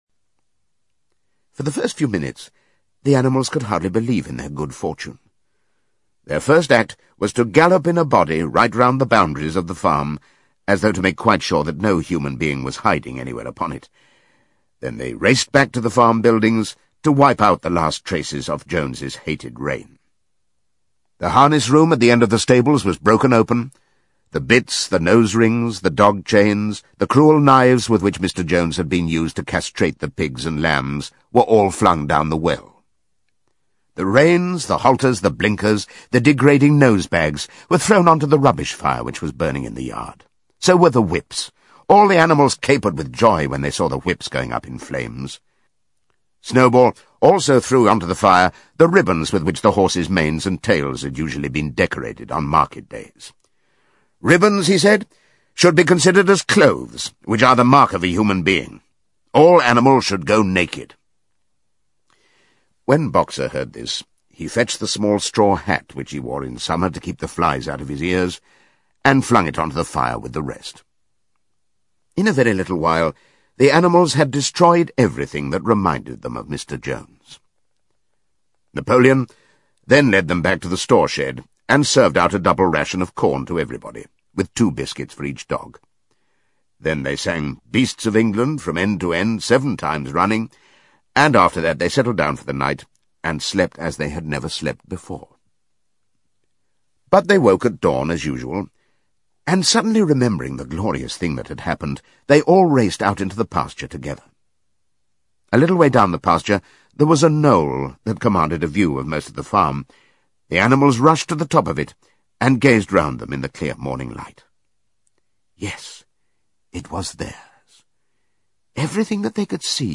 在线英语听力室动物农场Animal Farm 第2章(5)的听力文件下载,《动物农场Animal Farm》包含中英字幕以及地道的英语音频MP3文件，讲述了农场中饲养着各种牲畜，它们都是农场主人的私有财产，后来农场掀起了一场由猪领导的革命。